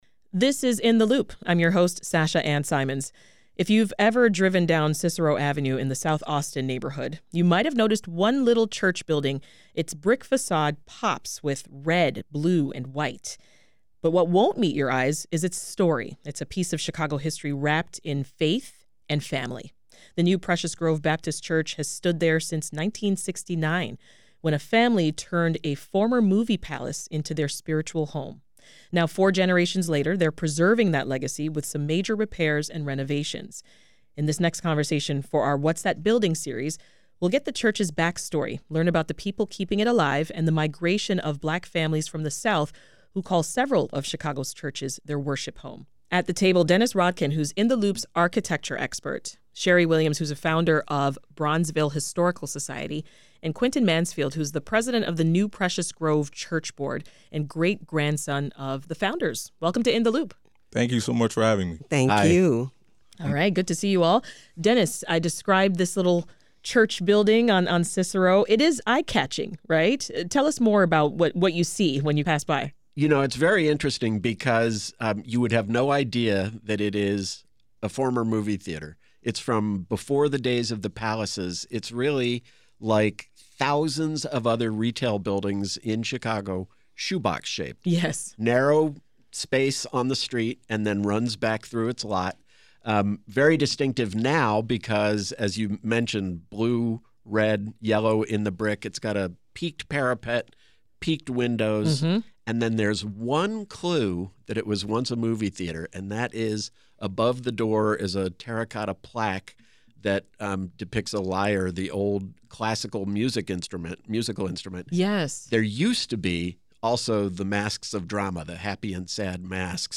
… continue reading 3143 פרקים # WBEZ Chicago # News Talk # News # On Covid19